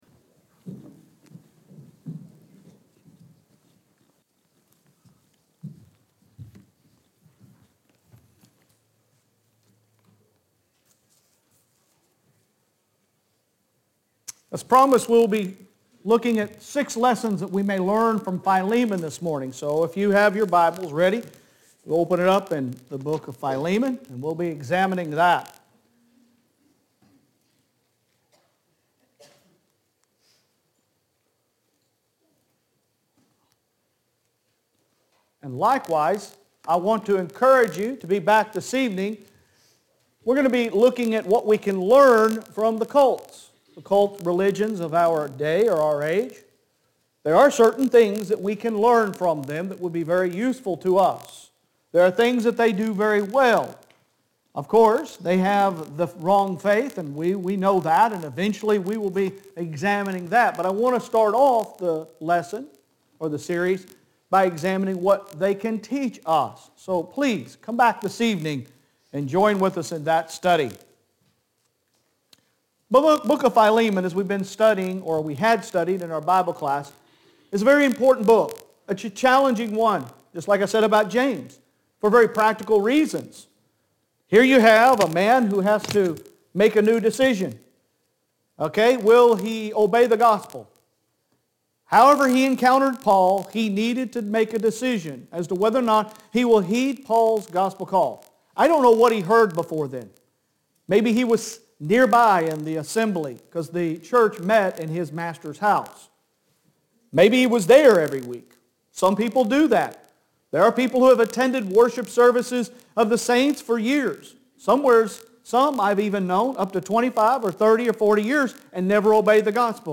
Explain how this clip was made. Sun AM Worship 04.10.22